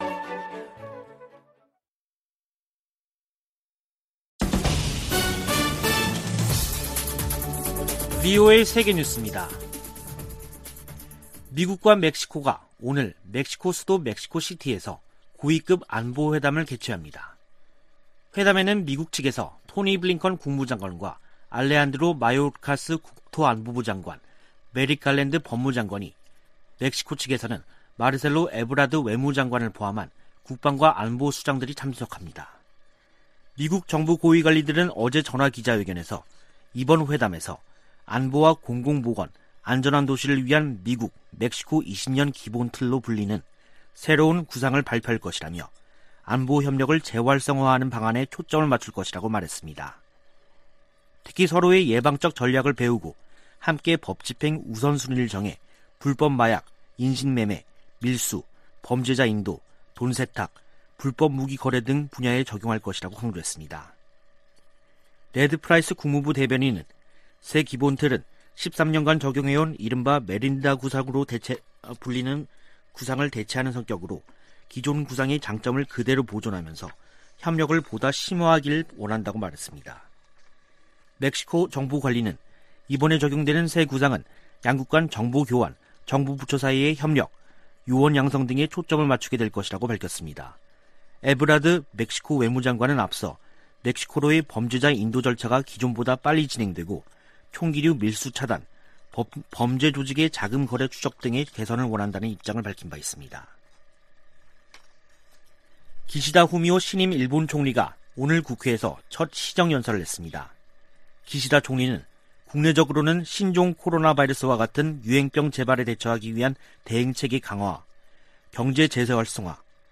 VOA 한국어 간판 뉴스 프로그램 '뉴스 투데이', 2021년 10월 8일 2부 방송입니다. 코로나 방역 지원 물품이 북한에 도착해, 남포항에서 격리 중이라고 세계보건기구(WHO)가 밝혔습니다. 대북 인도적 지원은 정치 상황과 별개 사안이라고 미 국무부가 강조했습니다. 북한에서 장기적인 코로나 대응 규제 조치로 인권 상황이 더 나빠졌다고 유엔 북한인권 특별보고관이 총회에 제출한 보고서에 명시했습니다.